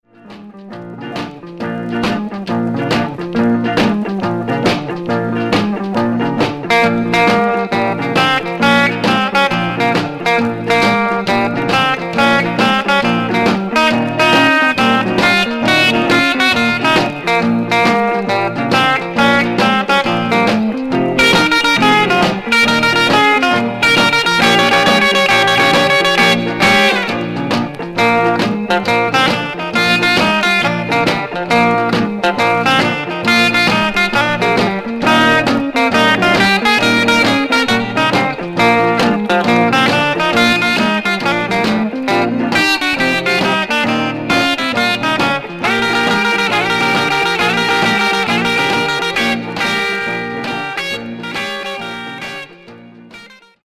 Mono
R&B Instrumental